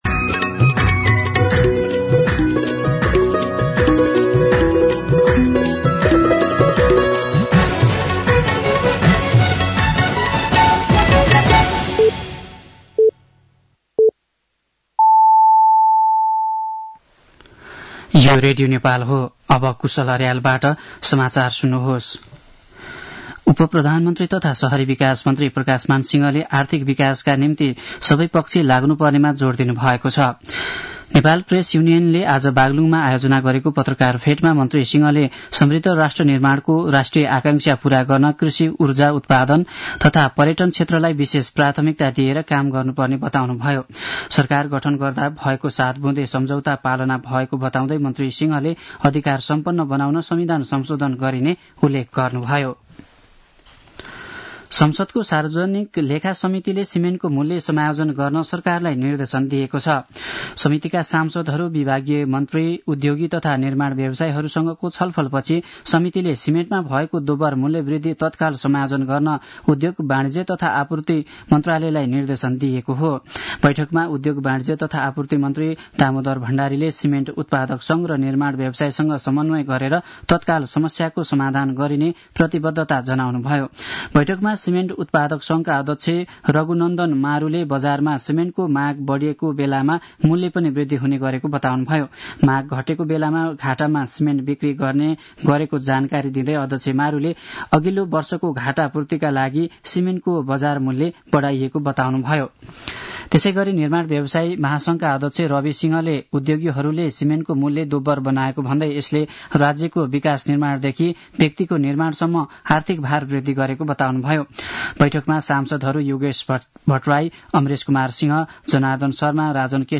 दिउँसो ४ बजेको नेपाली समाचार : १० माघ , २०८१